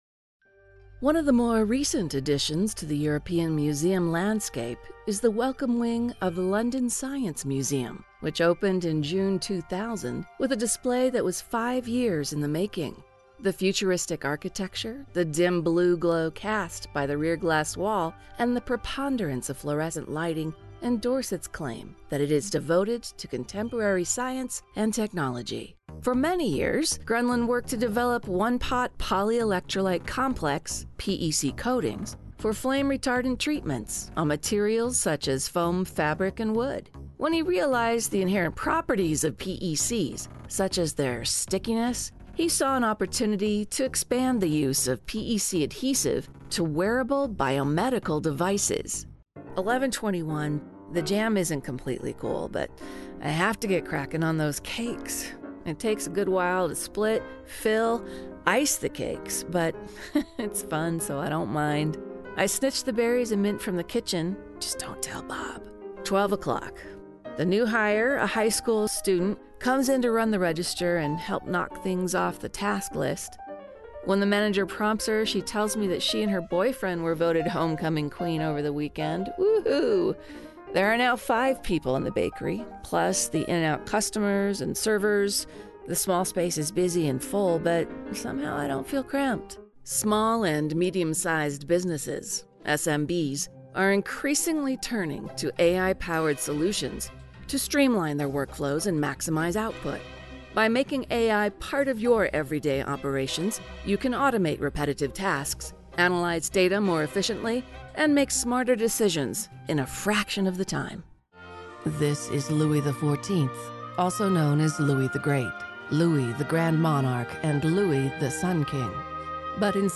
Voiceover Narration Female